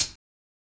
HI HAT KIT.wav